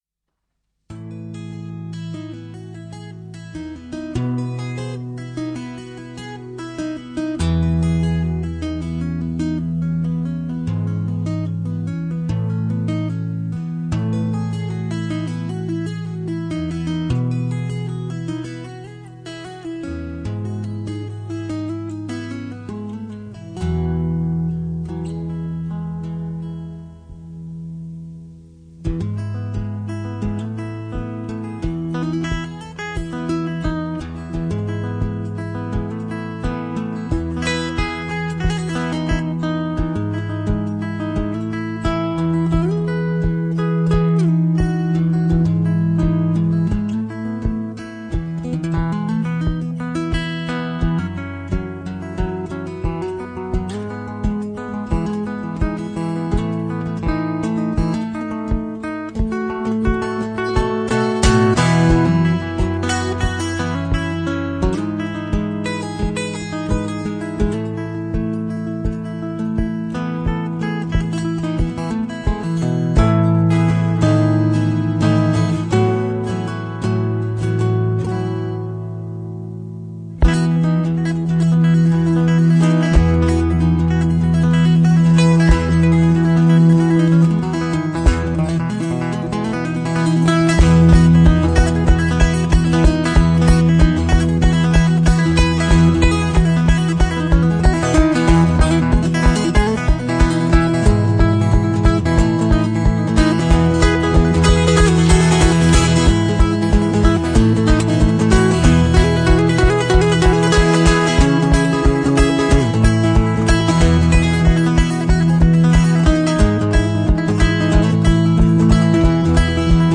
Som ambiente -